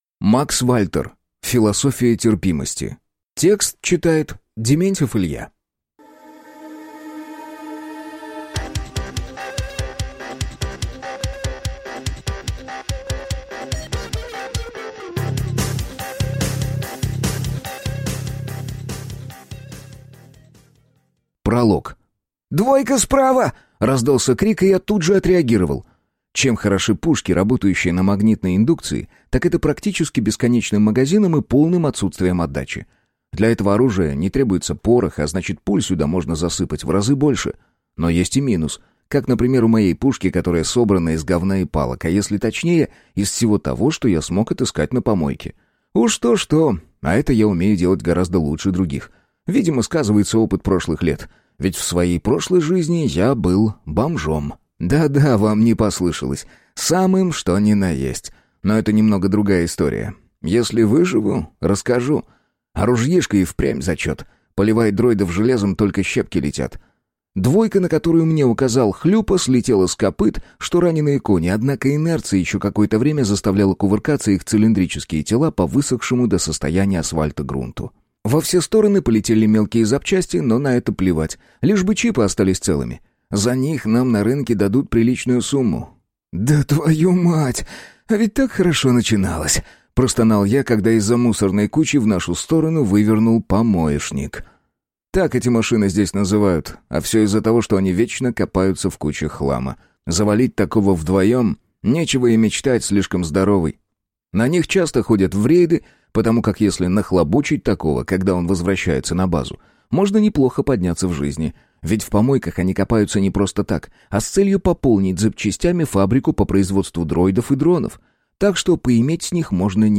Аудиокнига Философия терпимости | Библиотека аудиокниг